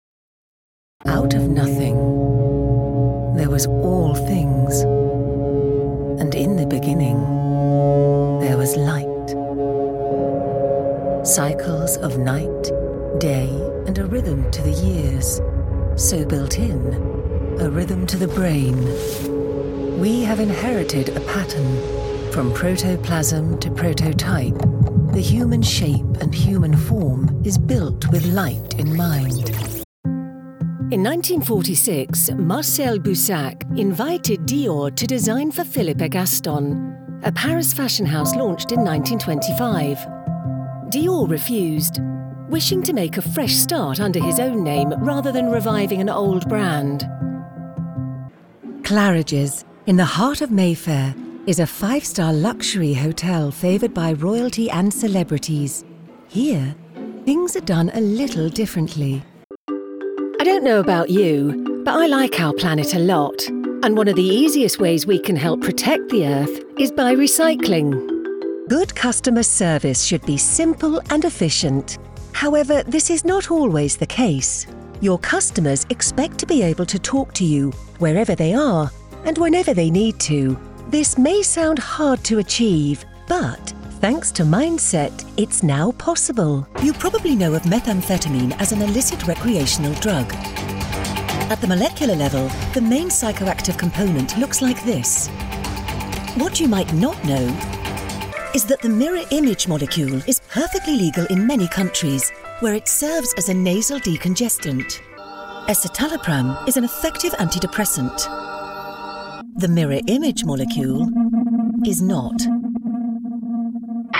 Velvety, smooth and sophisticated UK voice actor with a multitude of character voices!
Narration Demo
Narrative demo Reel - 2024.mp3